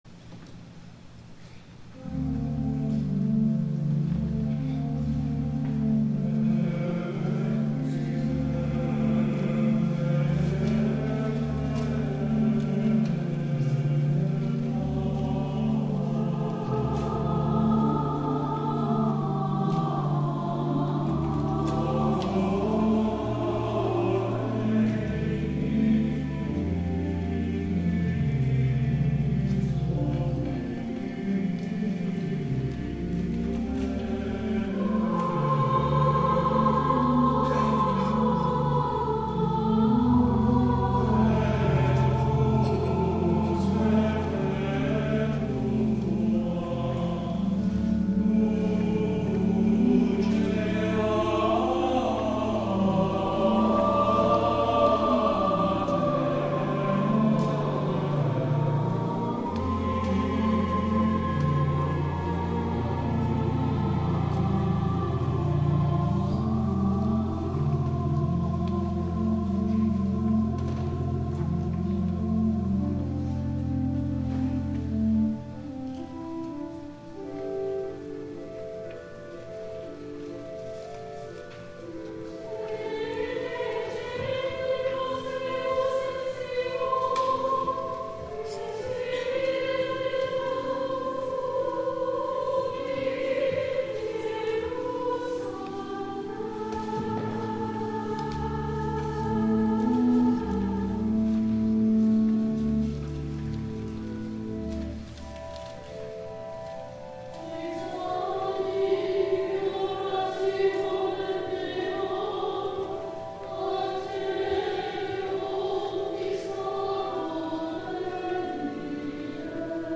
THE CHORAL PRAYER OF CONFESSION
The Chancel Choir
organist